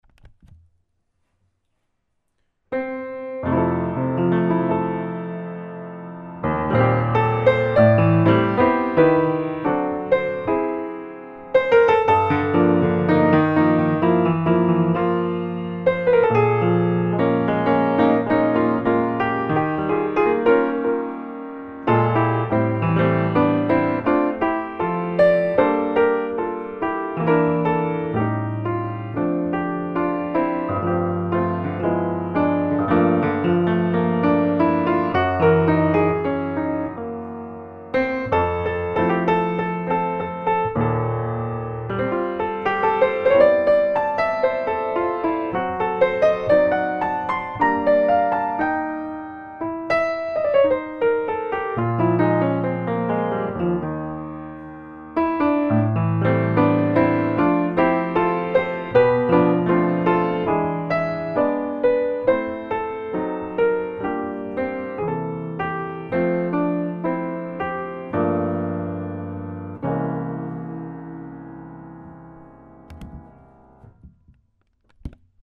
la deuxième enrichi légèrement l'harmonie